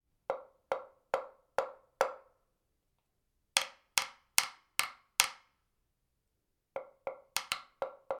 Tromme av kokosnøtt
Tromme av kokosnøtt er et sjarmerende lite perkusjonsinstrument som byr på varme klanger, raske rytmer og et smil hver gang du spiller. Denne kompakte kokosnøttrommen leveres med en liten filt-kølle og gir deg både mørke toner og lyse, perkusjonsaktige klikk – alt etter om du dekker hullet eller lar det stå åpent.
Åpent hull gir en fyldigere og mykere klang, mens lukket hull skaper et mer konsentrert anslag.
• Naturlig og varm tone – hver tromme har sitt eget uttrykk.